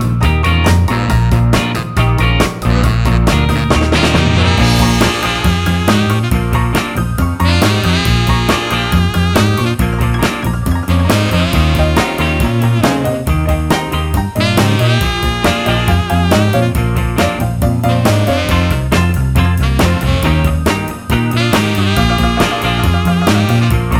Rock 'n' Roll